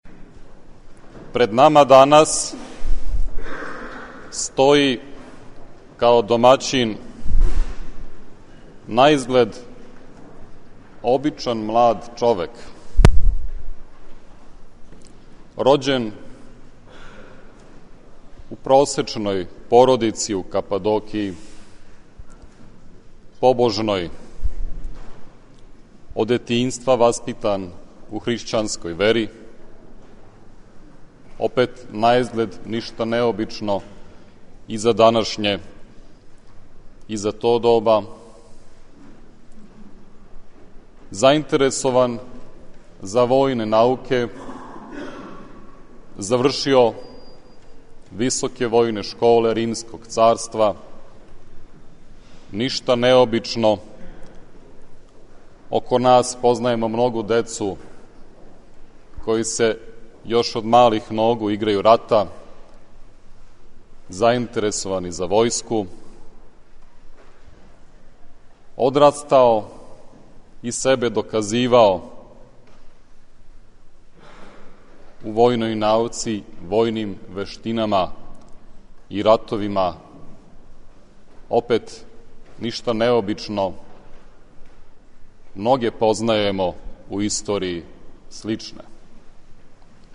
Прослава славе Саборног храма у Новом Саду
Беседа